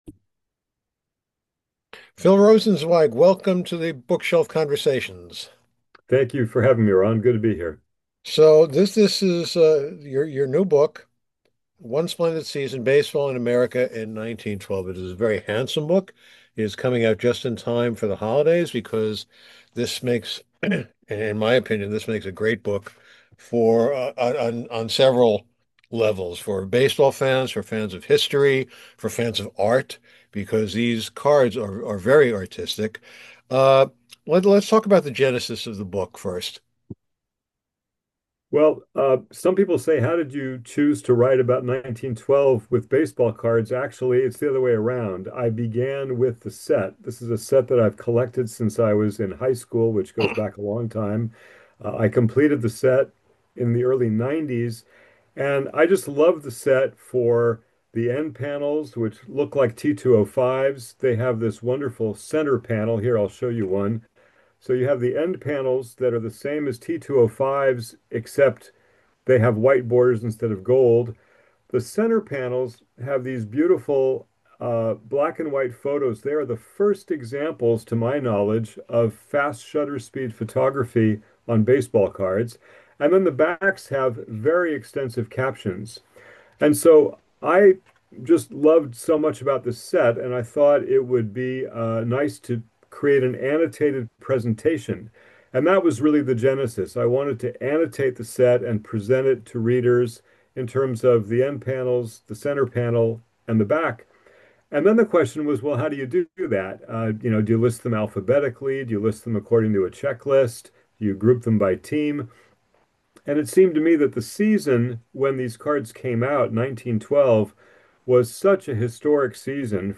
For some reason, despite my best efforts, the video did not come out in not one, but two attempts.
Prior to the pandemic, I used to do these Conversations by phone, so let us return to yesteryear…